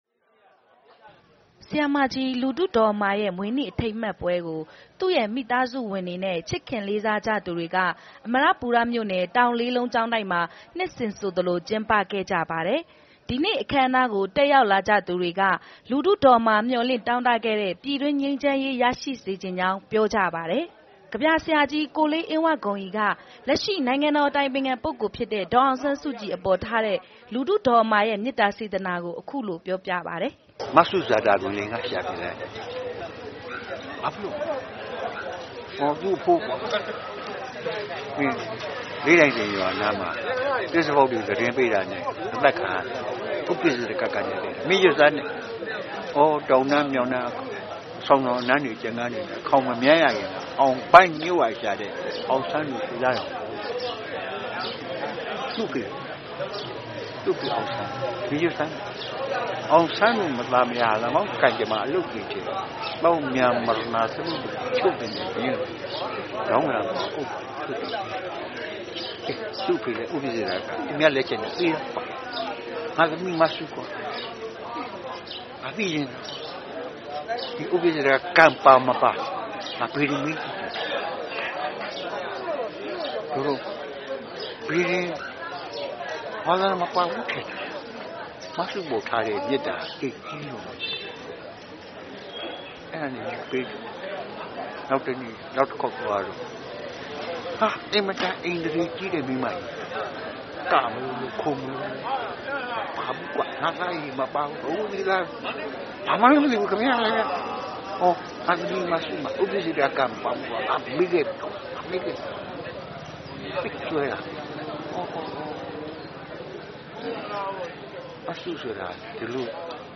သတင်းစာဆရာ၊ စာရေးဆရာမကြီး လူထုဒေါ်အမာရဲ့ ၁၀၃ နှစ်မြောက်မွေးနေ့ အထိမ်းအမှတ်ပွဲကို အမရပူရမြို့နယ်၊ တောင်လေးလုံးကျောင်းတိုက်မှာ ဒီနေ့ မနက်ပိုင်းက ကျင်းပခဲ့ပါတယ်။